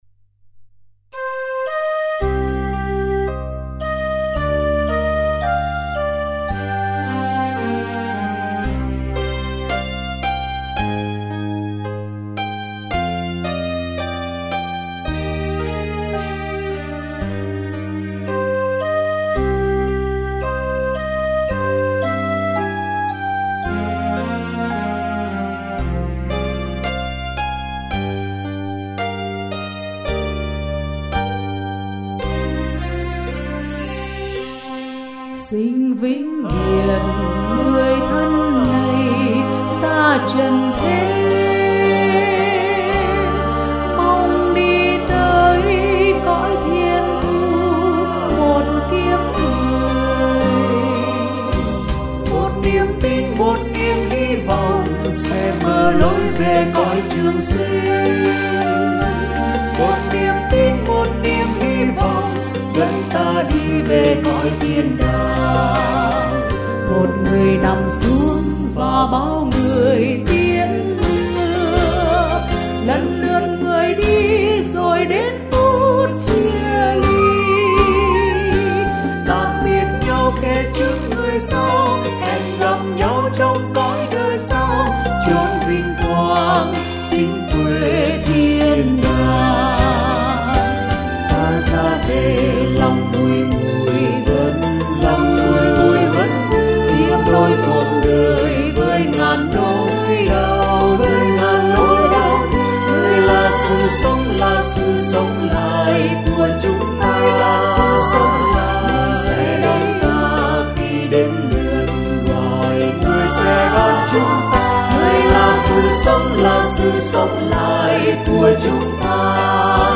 Thể loại 🌾 Nhạc Thánh Ca, cầu hồn, 🌾 Thánh ca Imprimatur